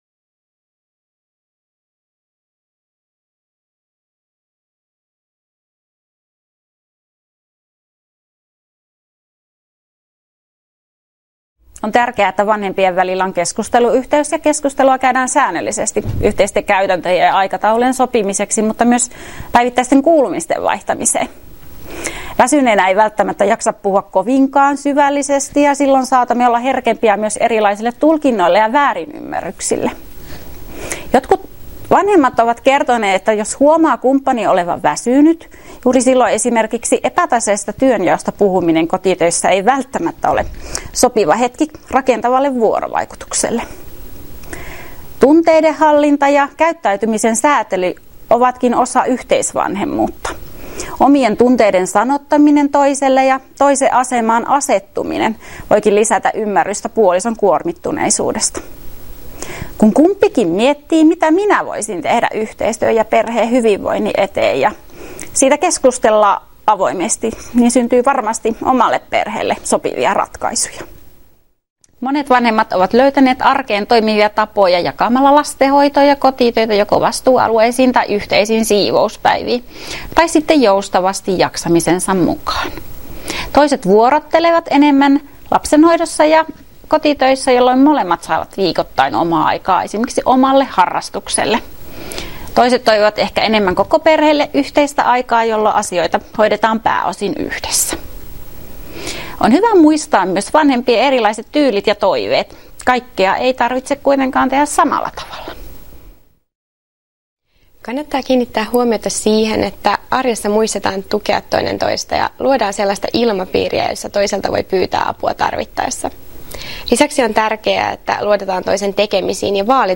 Asiantuntijoiden haastattelu 2 — Moniviestin